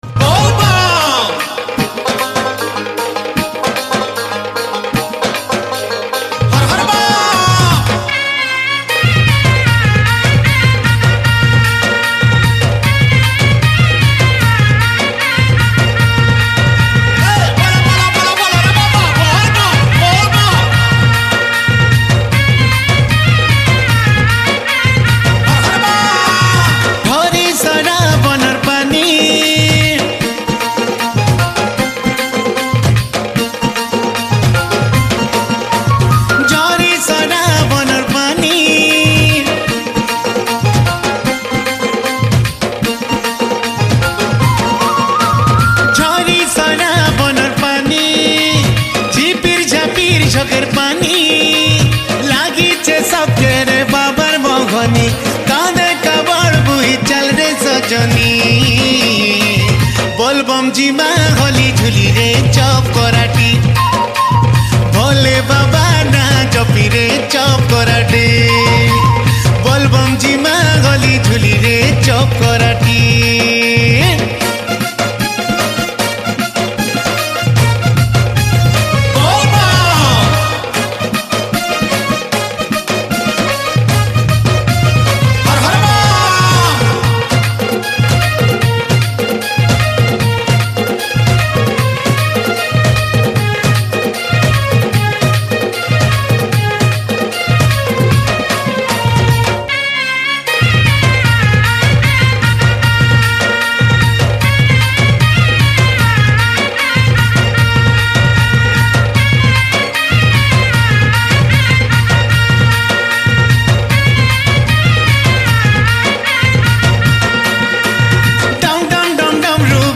Category: Bolbum Special Odia Songs